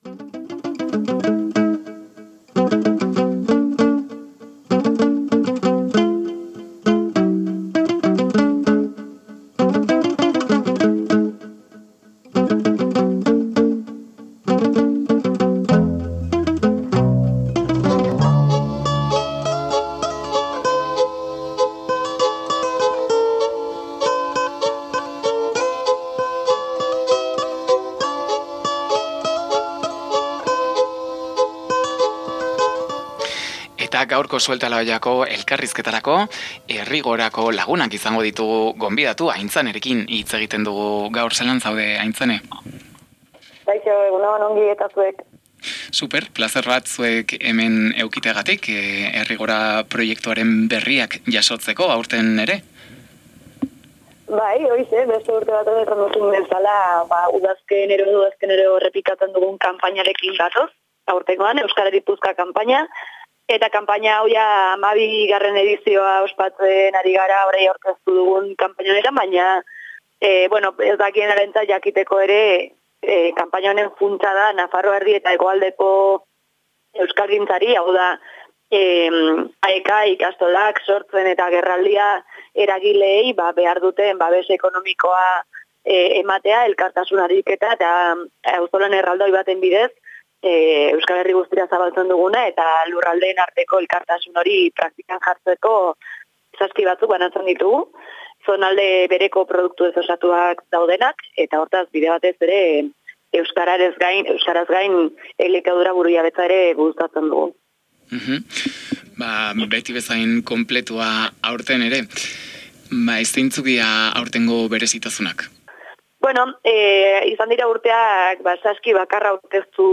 Elkarrizketa osorik: Suelta la olla: Errigorako kanpaina abian da! 00:15:54 4 0 0 Hala Bedi babestu nahi duzu?